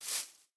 sounds / step / grass5.ogg
grass5.ogg